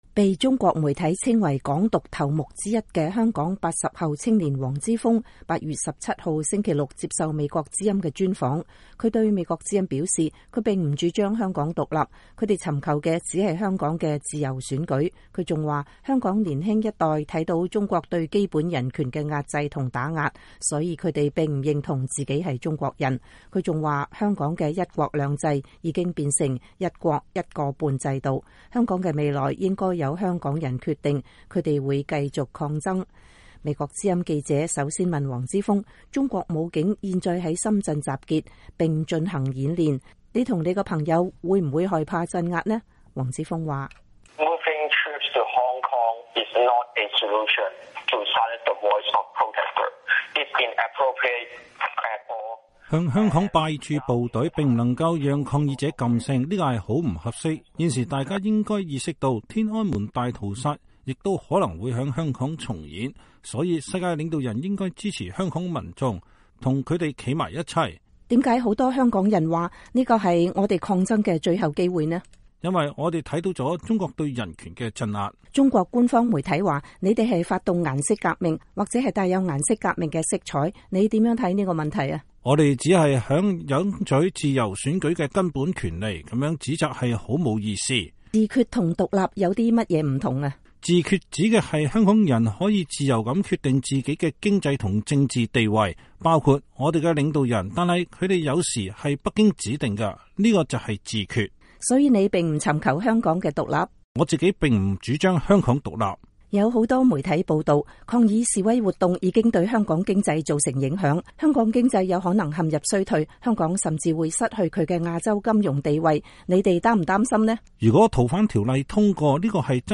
專訪黃之鋒：天安門事件有可能在香港重演 我並不主張香港獨立